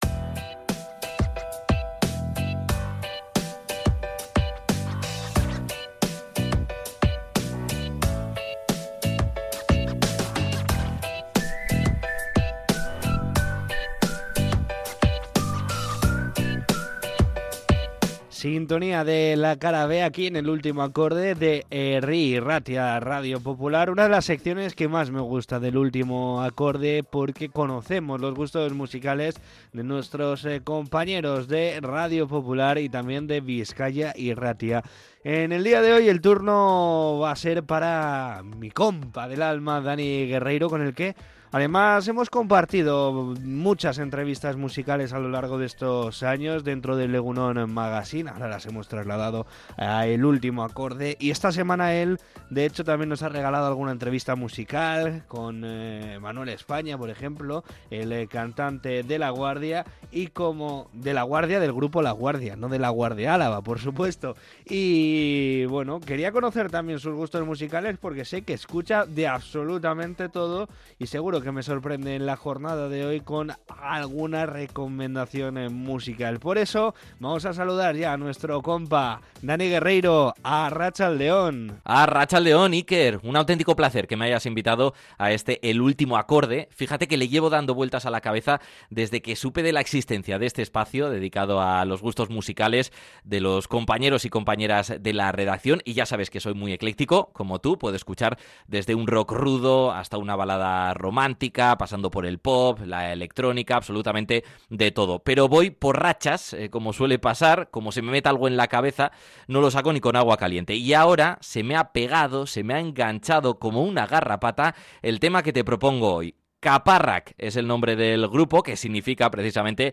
Nos ha presentado a Kaparrak, una banda que fusiona a la perfección el euskera con la alegría y el desparpajo del sur.
Se trata de una fantástica versión en euskera del clásico himno «El aire de la calle» de Los Delinqüentes, una demostración perfecta de que el buen ritmo y las ganas de fiesta no entienden de fronteras.